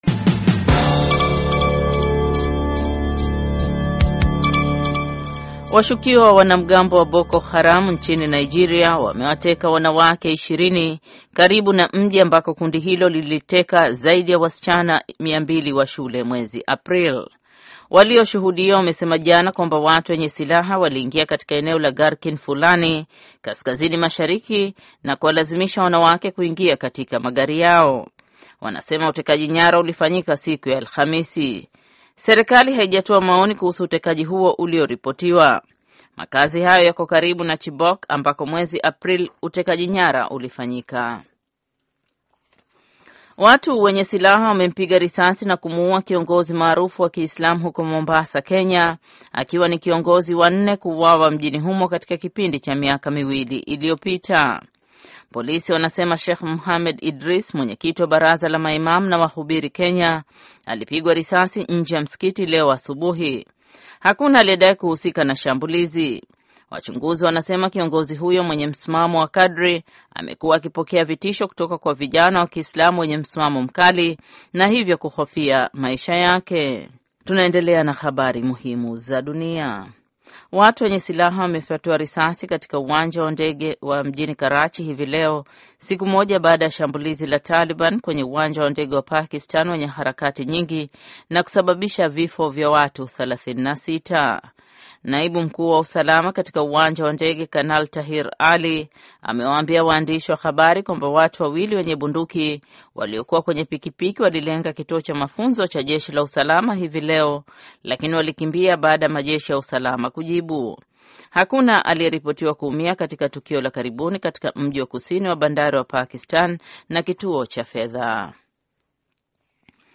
Taarifa ya Habari VOA Swahili - 6:22